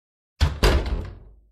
Puerta cerrando - Botón de sonido
Puerta cerrando
puerta-cerrando.mp3